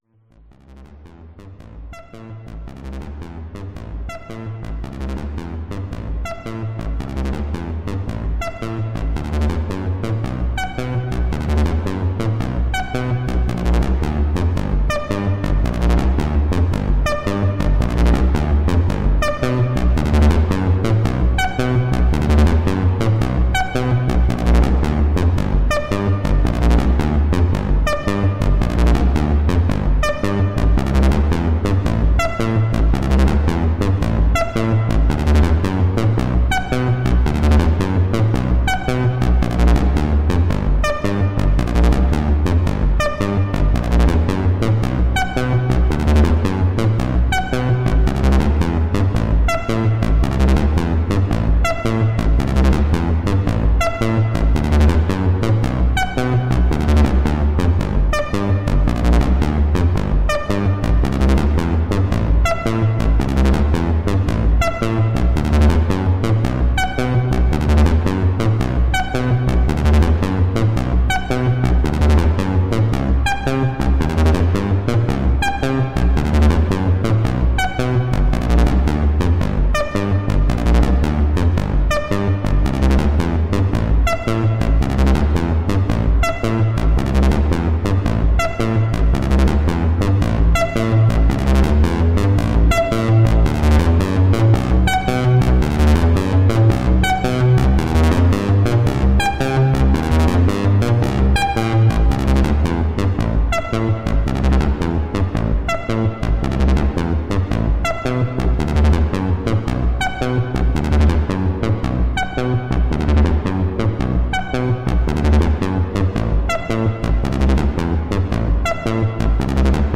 Kopfhörer oder Stereo-Lautsprecher empfohlen.
2x Behringer Crave
Strymon Nightsky
FX Delay